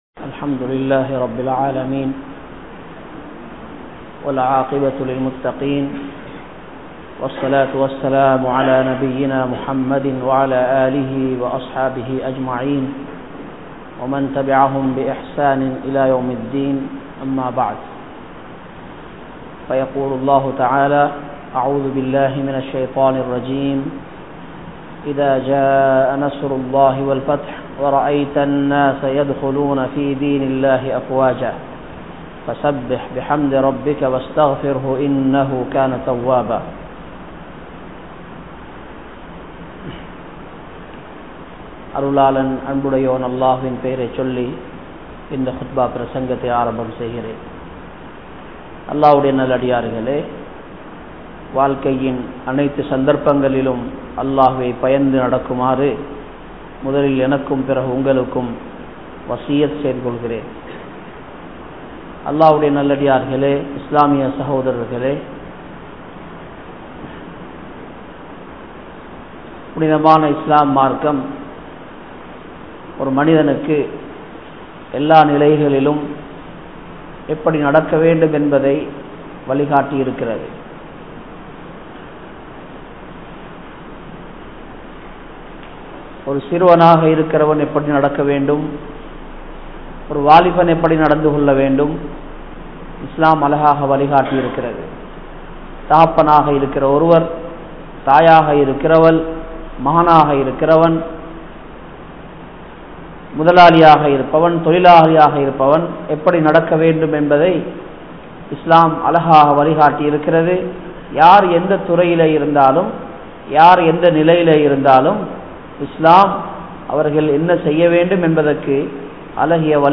Maranaththin Pidi (மரணத்தின் பிடி) | Audio Bayans | All Ceylon Muslim Youth Community | Addalaichenai